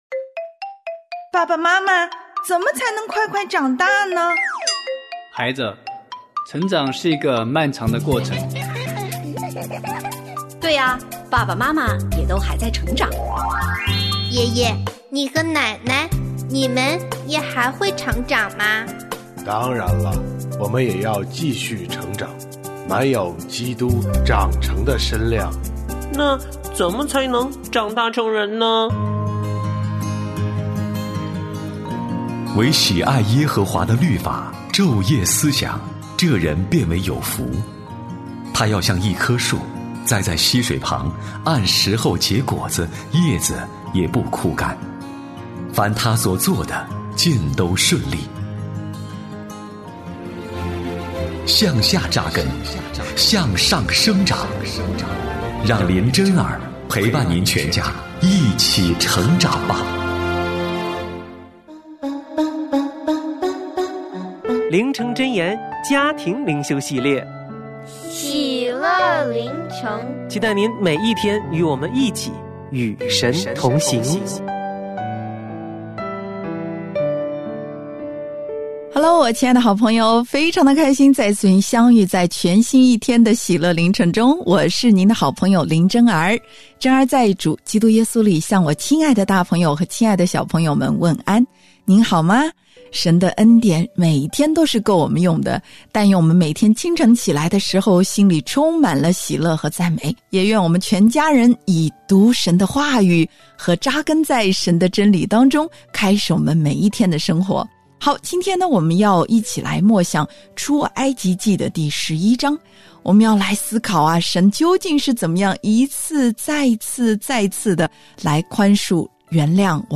我家剧场：圣经广播剧（154）约阿施下令重修圣殿；约阿施面临攻打